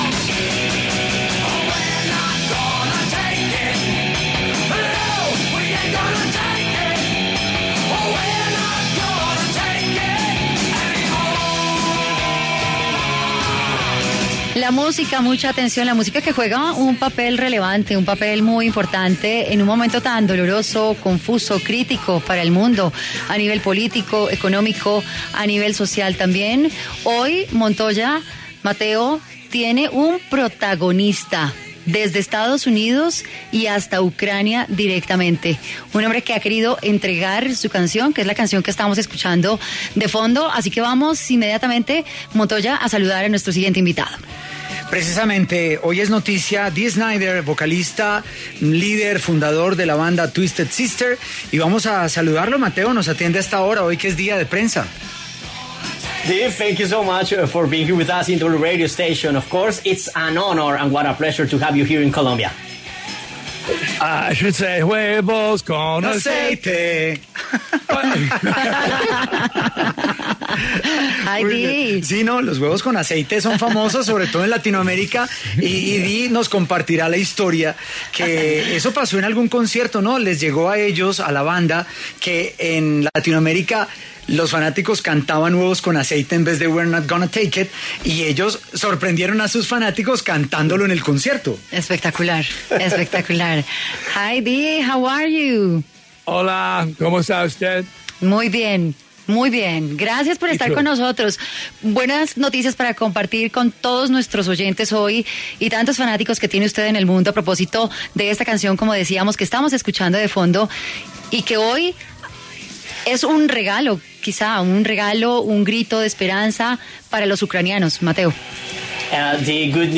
Dee Snider, el mítico cantante de la banda Twisted Sister, habló para Contrarreloj sobre su aprobación de esta canción como “grito de guerra” en Ucrania.